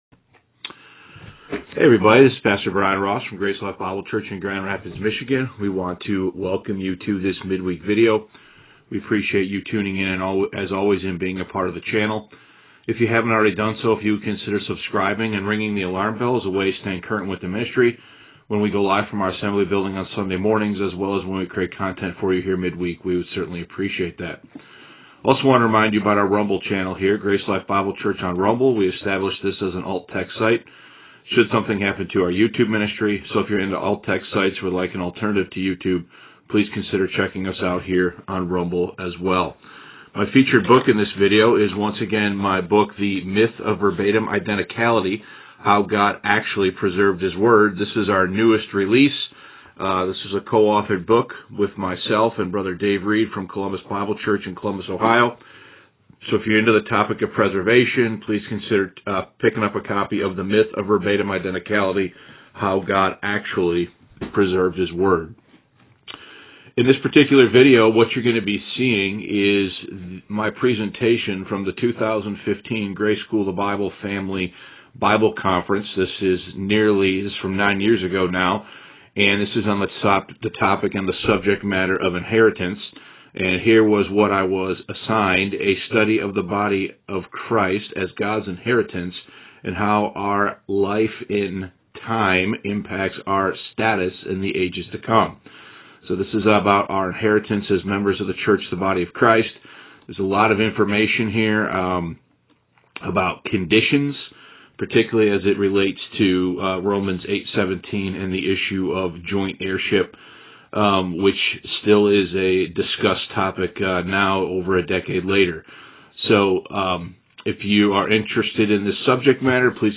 Inheritance (2015 GSB Conference Message)